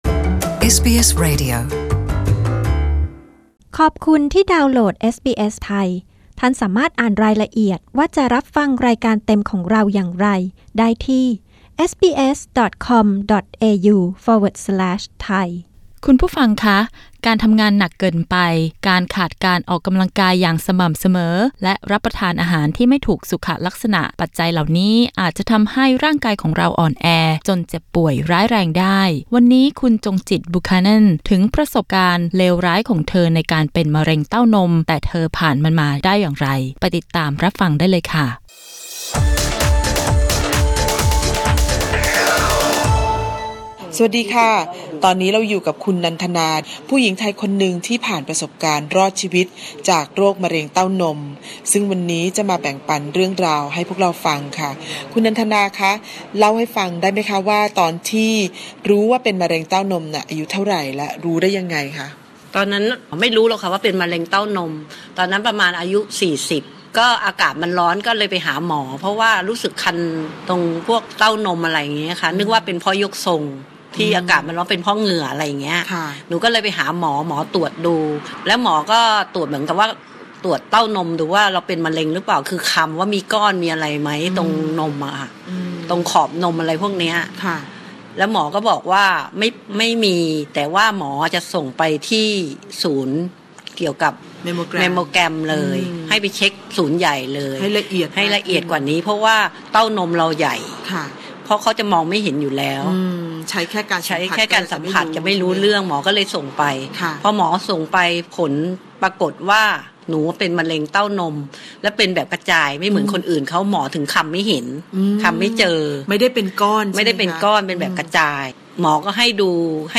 กดปุ่ม 🔊 ด้านบนเพื่อฟังสัมภาษณ์เรื่องนี้